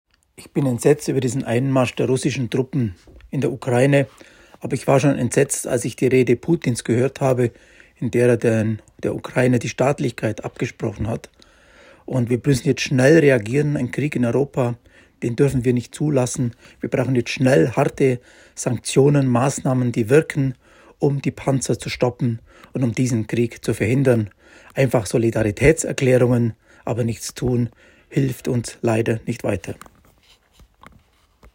O-Ton zum Download